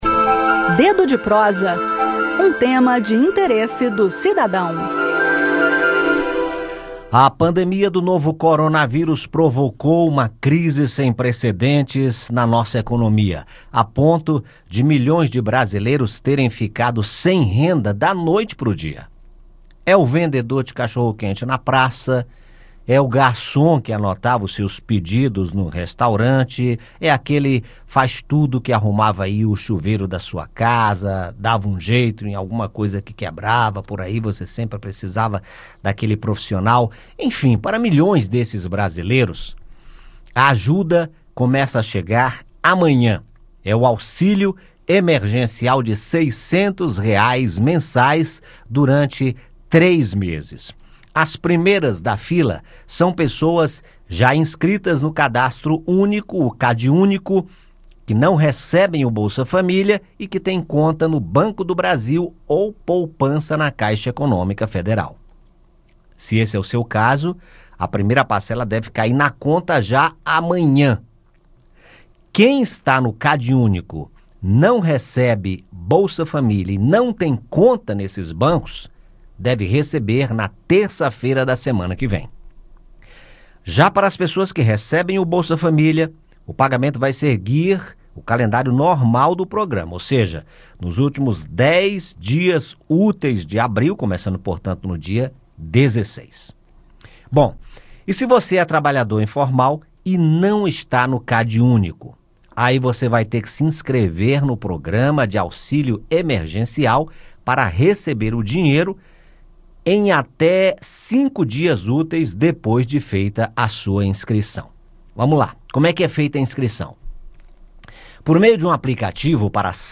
O aplicativo possibilita o cadastro para o recebimento de ajuda emergencial de R$ 600 mensais pelo período de três meses. O quadro “Dedo de Prosa”, do programa Conexão Senado, da Rádio Senado, traz detalhes sobre o uso do aplicativo.